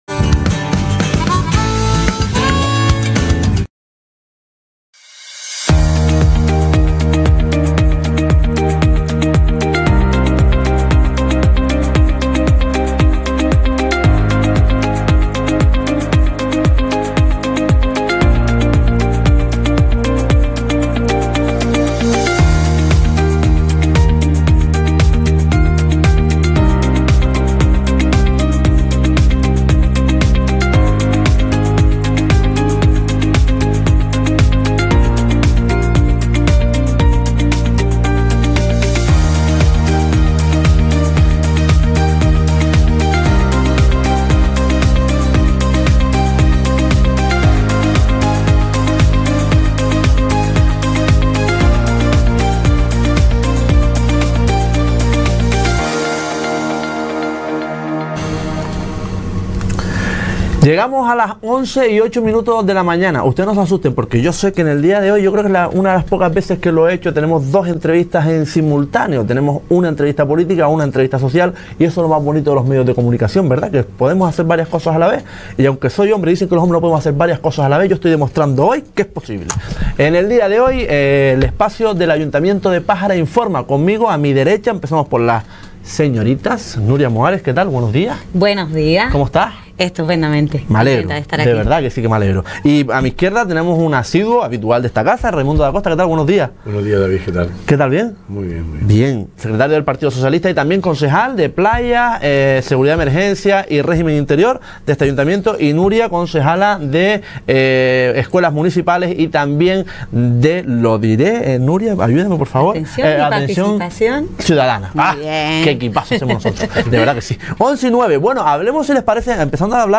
El pasado miércoles 17 de abril nos visitó Raimundo Dacosta y Nuria Moares, concejales del grupo de gobierno de Pájara, para dar un amplio repaso a las conce